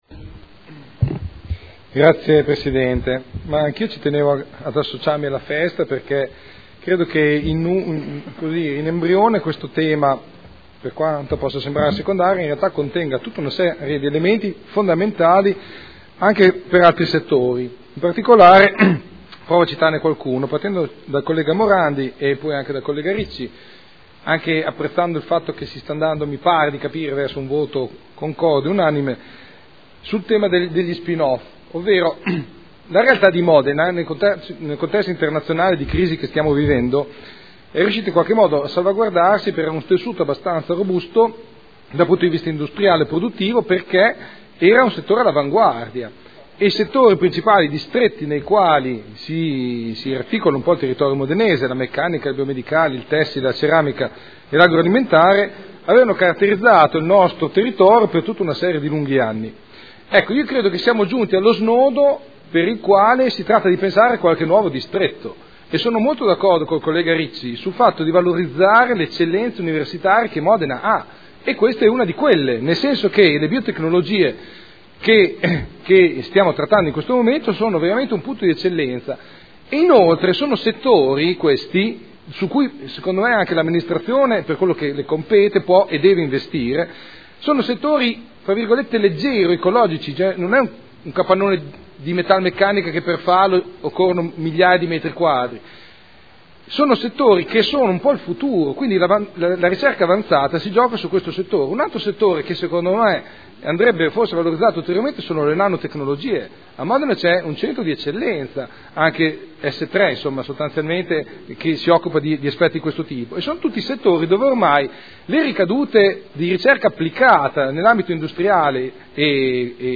Enrico Artioli — Sito Audio Consiglio Comunale